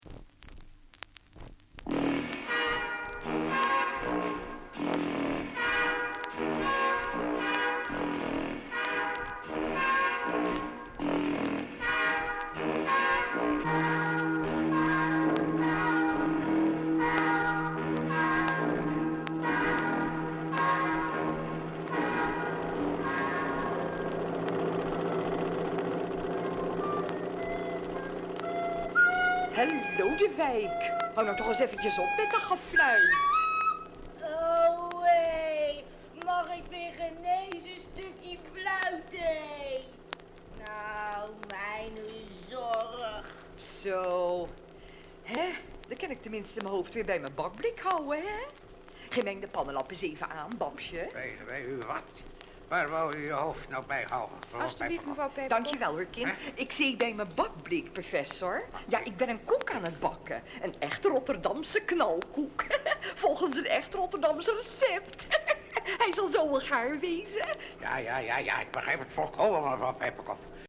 Hoorspel